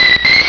pokeemerald / sound / direct_sound_samples / cries / remoraid.aif
-Replaced the Gen. 1 to 3 cries with BW2 rips.